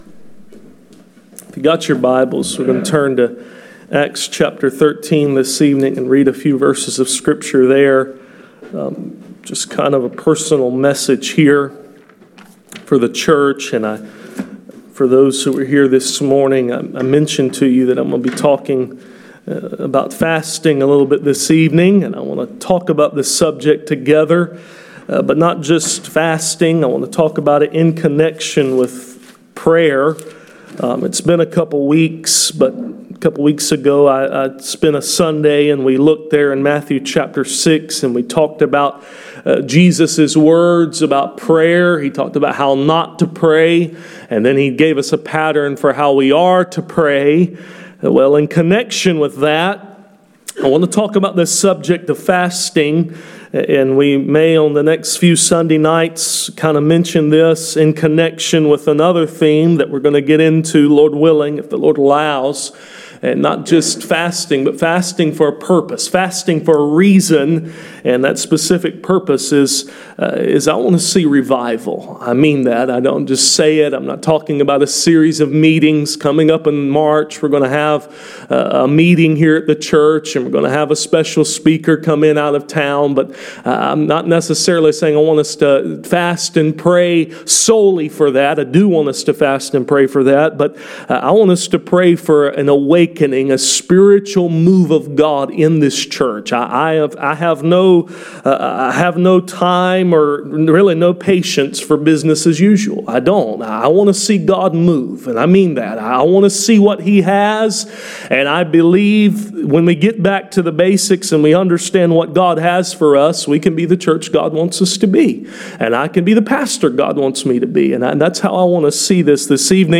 Passage: Acts 13:1-4 Service Type: Sunday Evening « Praying for the safe passage of our Little Ones A “fast” for unfailing waters »